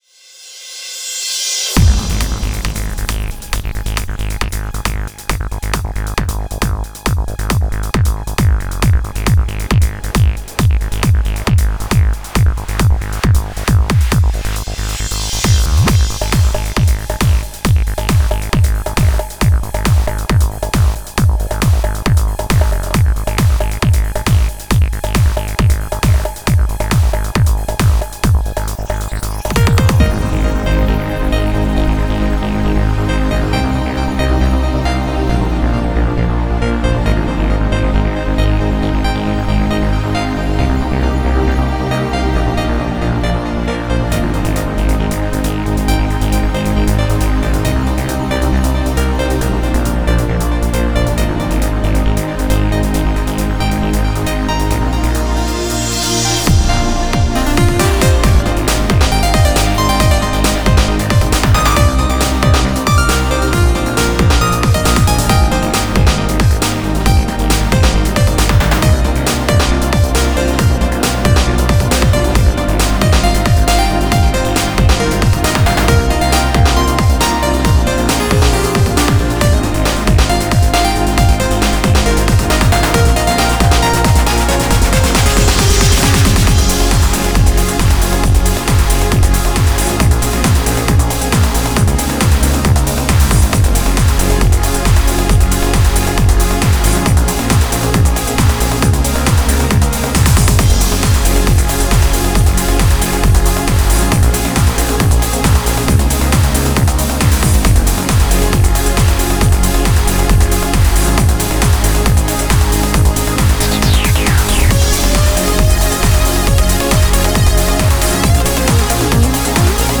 Melodic Trance